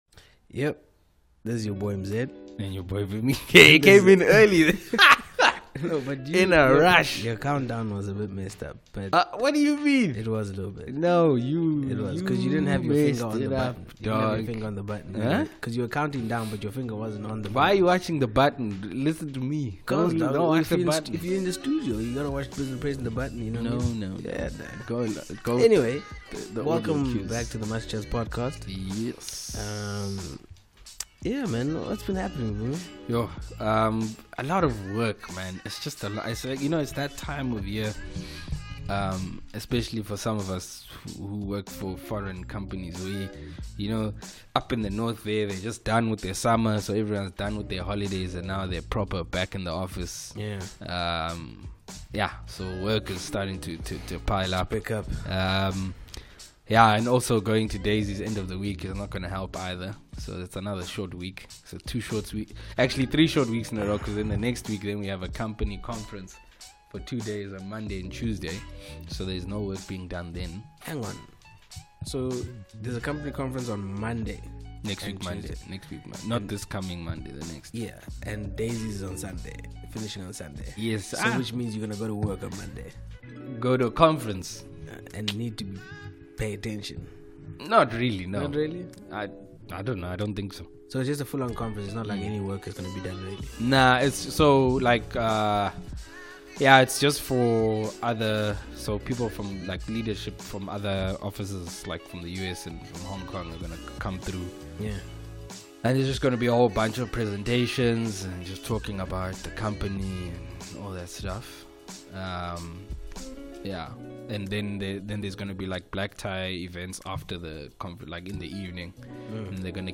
They delve into an amazing and insightful conversation about always keeping your life in perspective and living it to the fullest. The boys share their thoughts about motivation in life, goals and expectations.